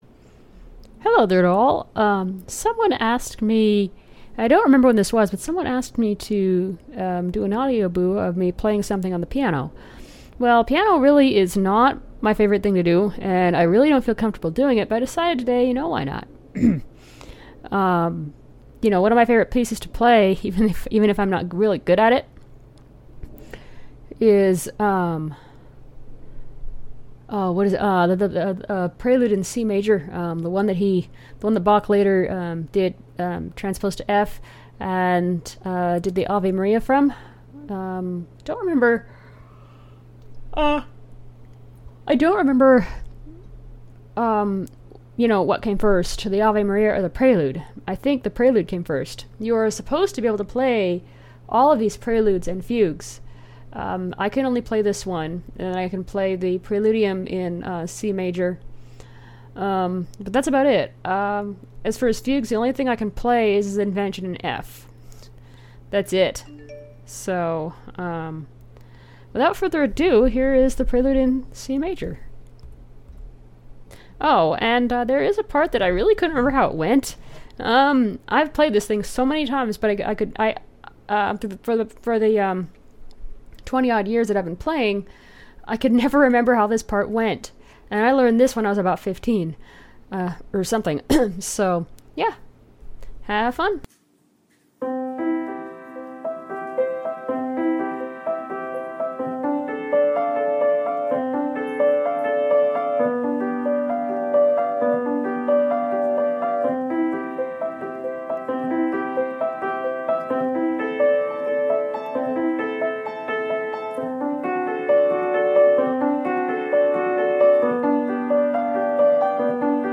I play bach prelude in c major on the piano